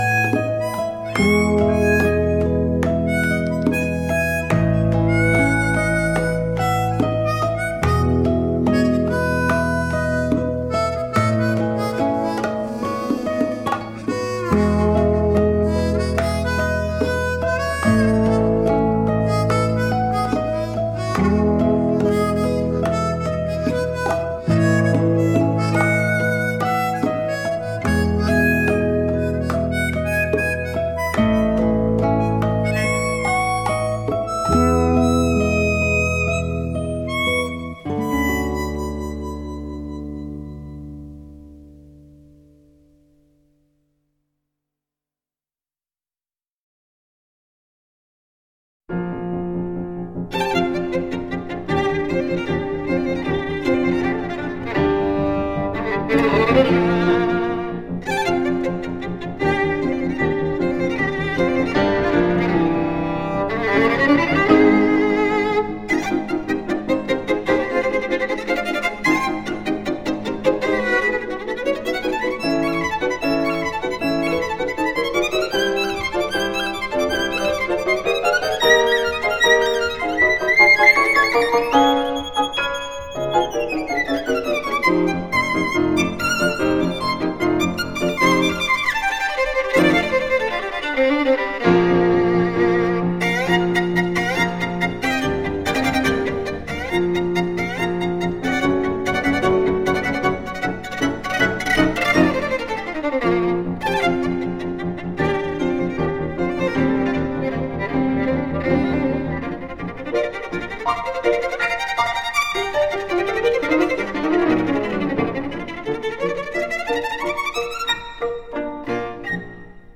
24BIT/192K DSP MASTERING